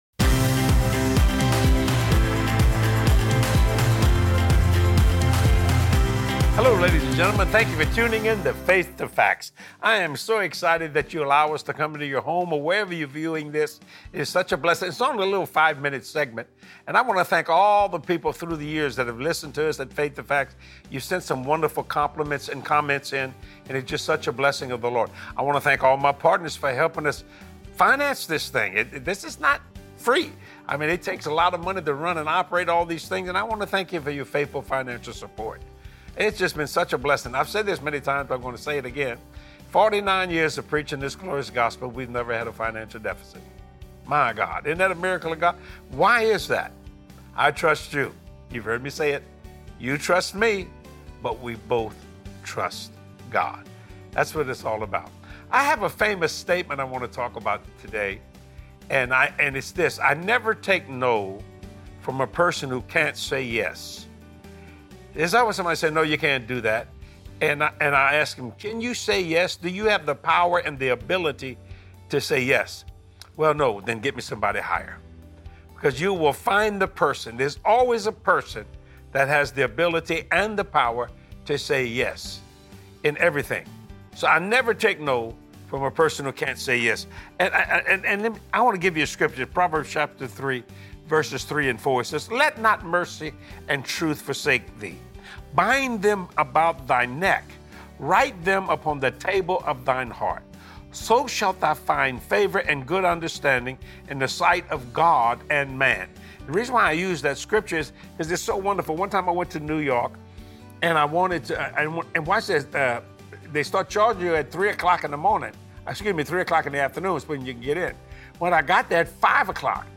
You have FAVOR with God and man! Be filled with boldness and confidence as you watch this empowering teaching with Jesse.